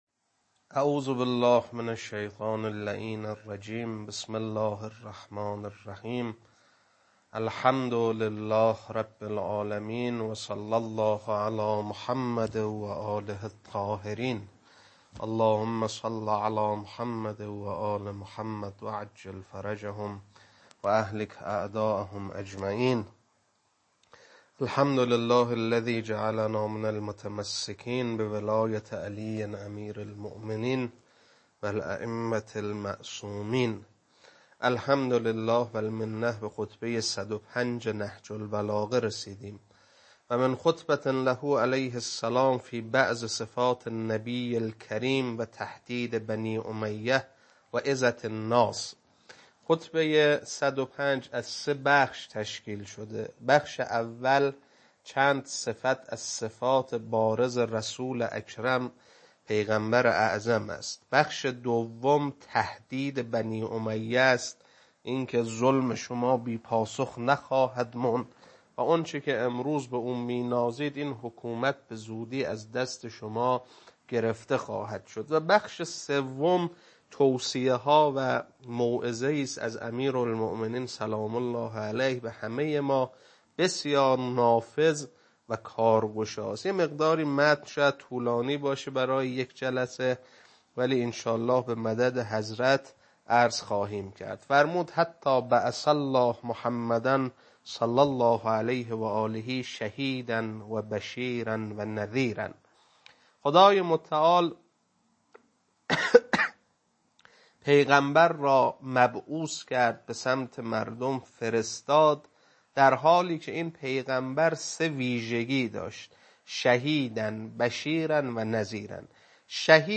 خطبه-105.mp3